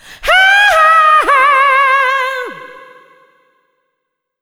SCREAM13  -R.wav